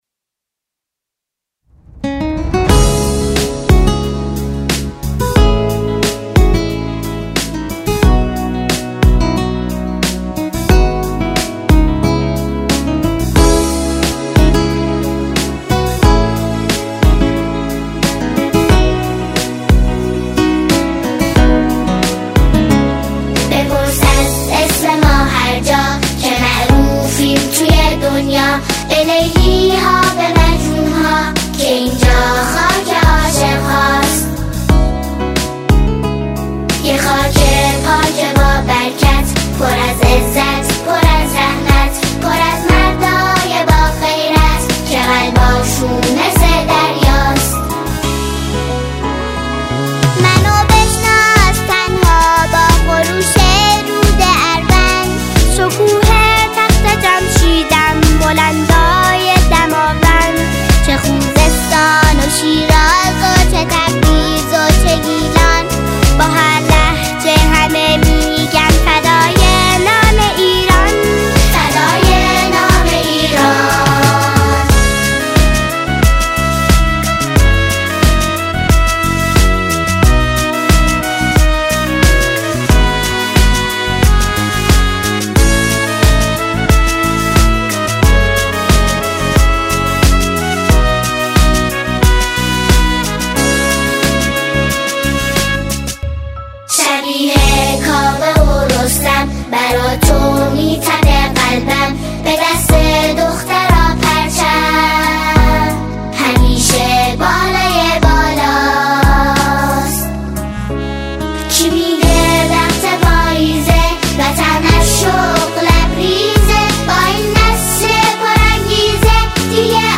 شعری را با موضوع ایران همخوانی می‌کنند